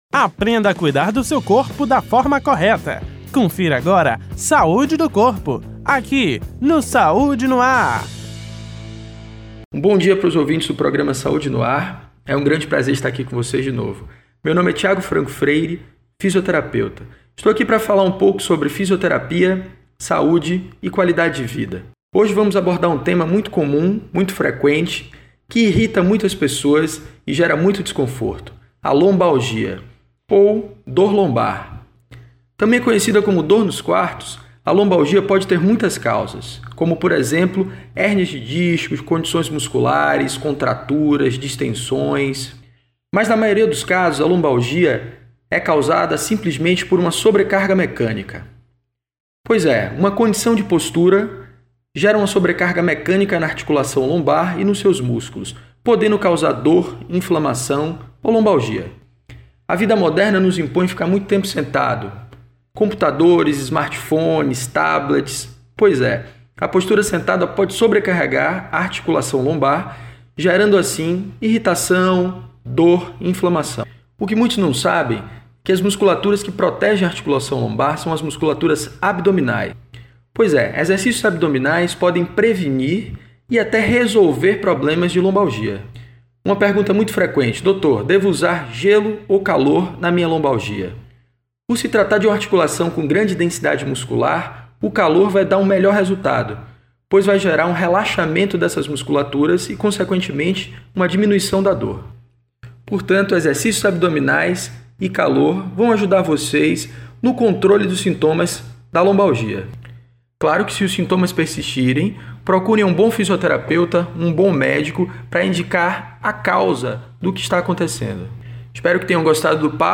O assunto foi tema do Quadro: Saúde do Corpo exibido toda segunda-feira no Programa Saúde no ar, veiculado pela Rede Excelsior de Comunicação: AM 840, FM 106.01, Recôncavo AM 1460 e Rádio Saúde no ar / Web.